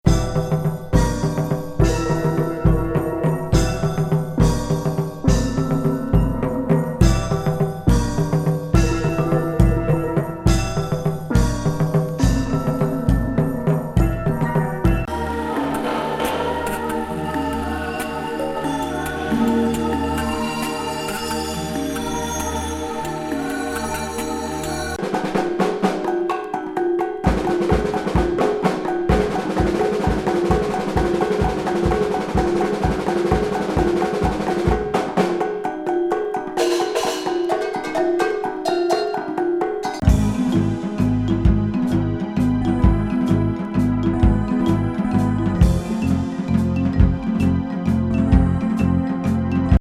デンマーク産オブスキュア・エクスペリメンタル・アヴァン・バンド89年作。
ワールド・ミュージックとエレクトロニクスが混在したストレンジな精神世界が
ズルズルに展開する異界音楽！